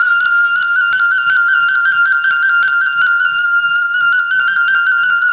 Drone FX 03.wav